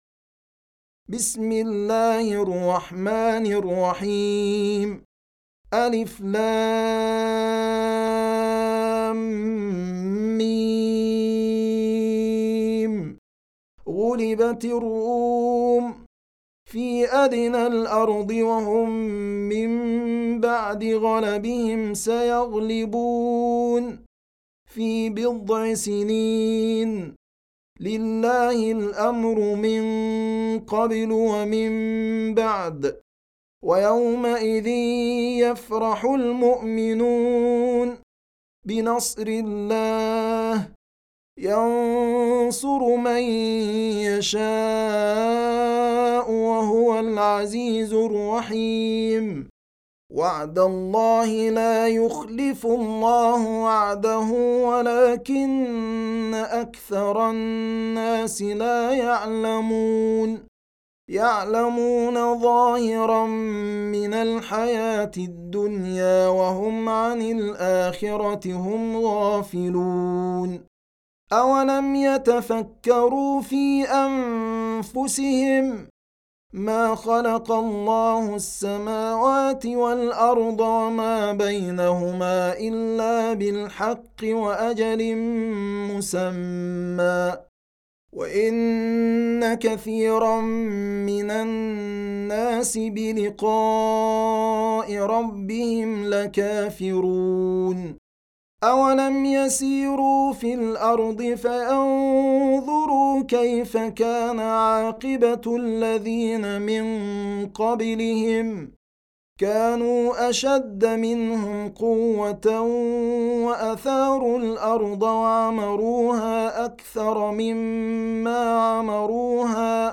Surah Sequence تتابع السورة Download Surah حمّل السورة Reciting Murattalah Audio for 30.